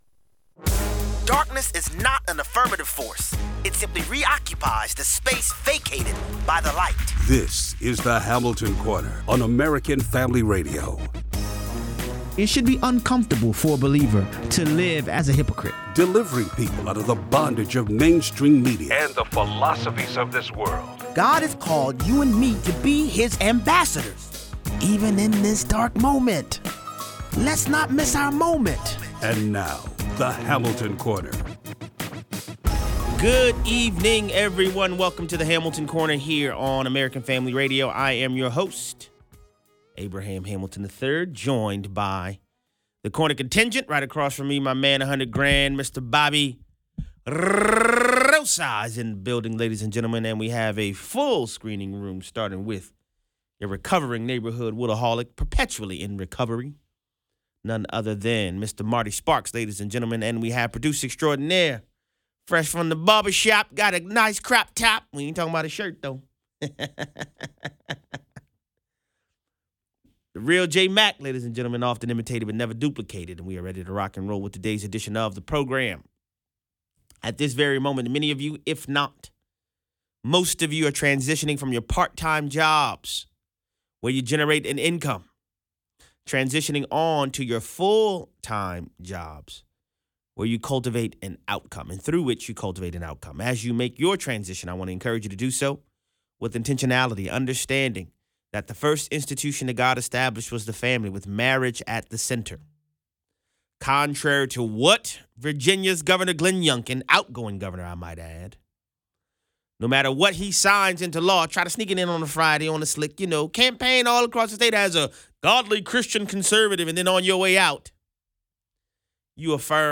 Haiti has descended into utter chaos. 31:00 - 48:00. Has the inglorious JRB been caught in another lie? Callers weigh in.